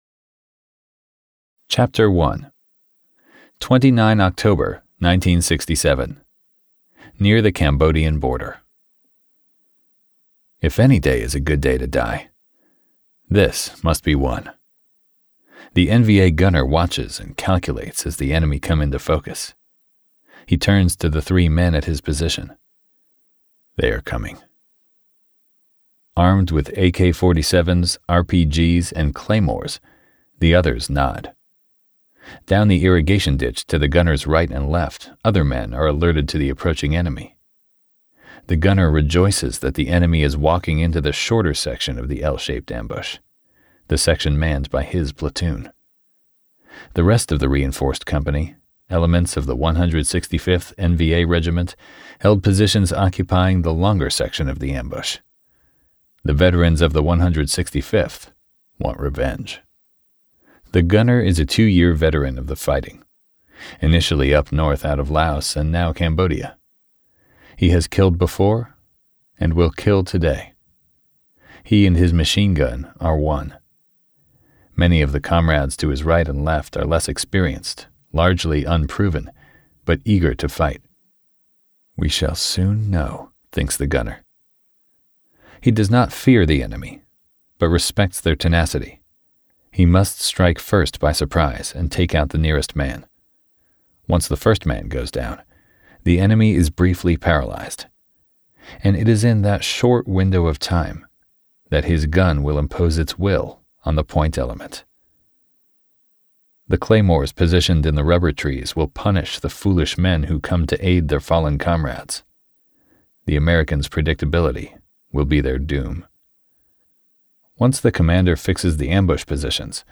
• Audiobook • 11 hrs, 38 mins